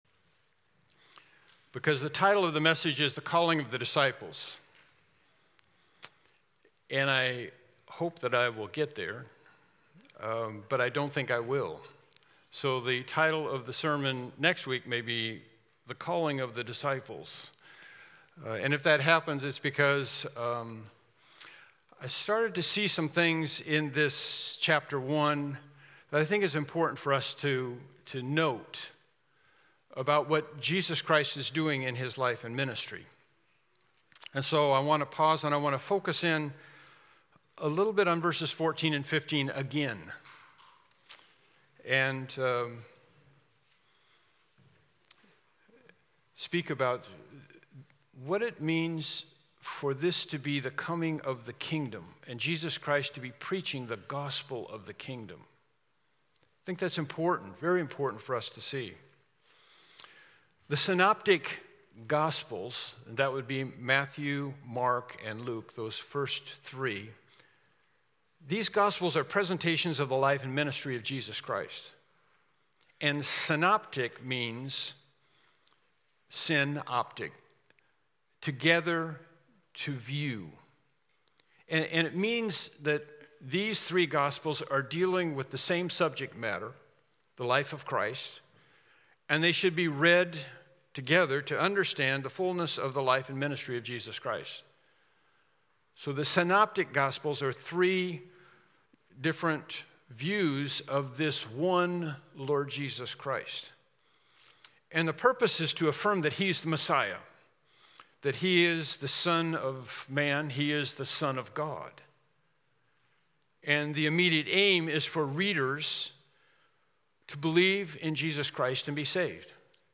Mark 1:14-15 Service Type: Morning Worship Service « Praise Yahweh